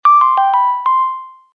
Message tone 10.mp3